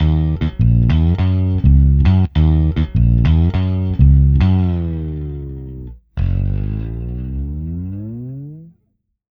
Ala Brzl 1 Bass-D#.wav